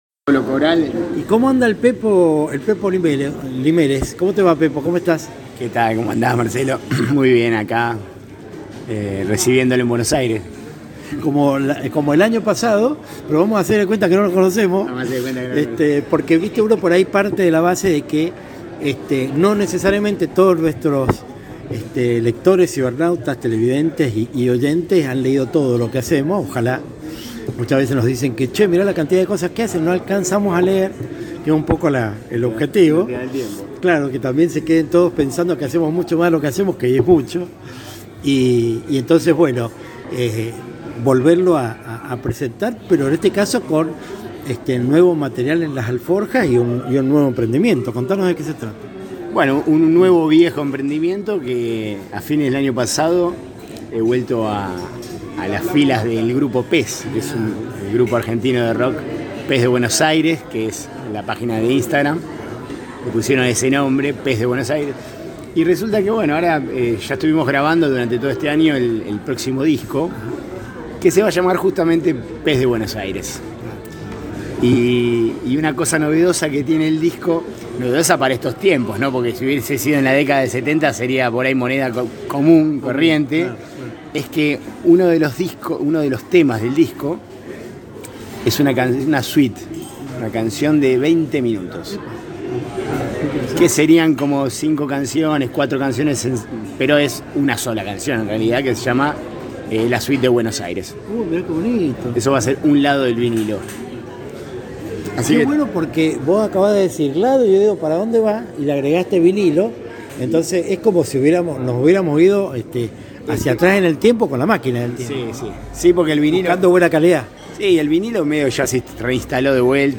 En este caso estuvimos sentados a una de las mesas del café Berna.